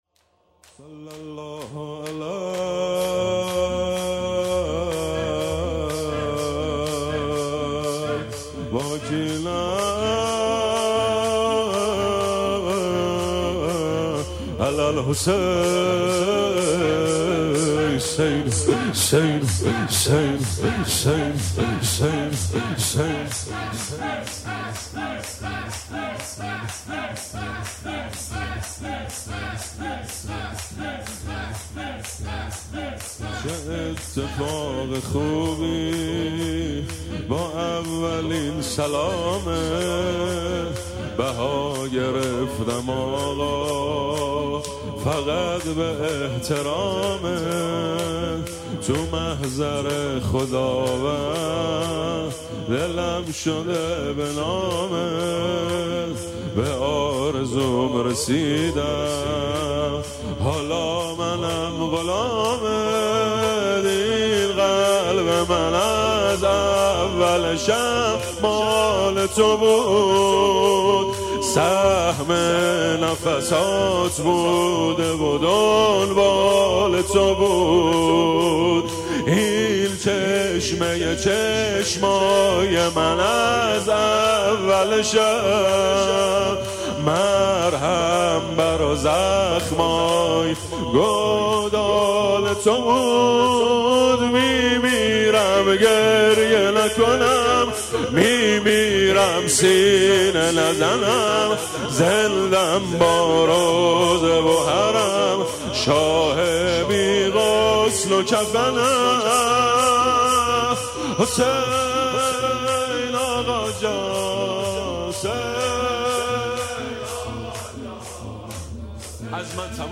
خیمه گاه - روضةالشهداء - شور چه اتفاق خوبی
شب هشتم ماه رمضان/ 20 فروردین 401 شور مداحی ماه رمضان اشتراک برای ارسال نظر وارد شوید و یا ثبت نام کنید .